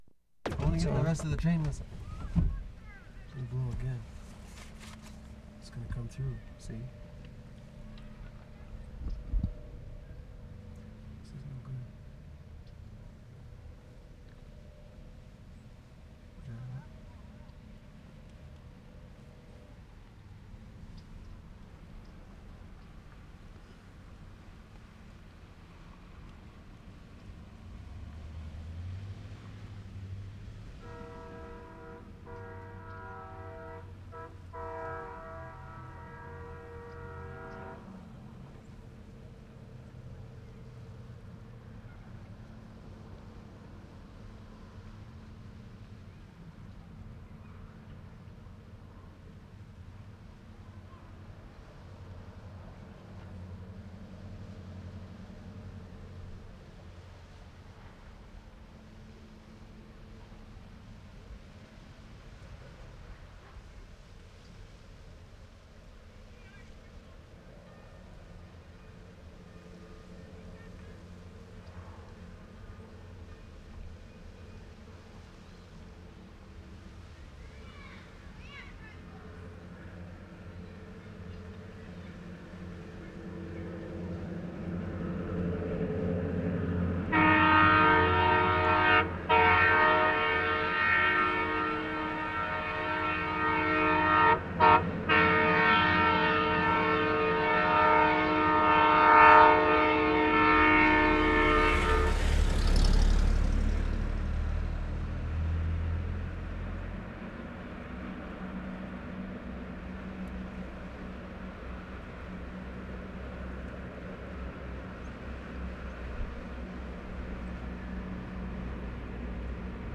VIKING AMBIENCE, around 5 p.m. 6'34"
2. Train whistle (0'30"), bell ringing (1'10"), close train whistle (1'25"), bell ringing in distance -- at train crossing? (2'30" - 4'10"). Children's voices and the occasional car passing. Church bell (5'30") ringing 5 p.m. (Big Ben type). During ring car passing. This take is interesting for its variety and the occurrance of specific events.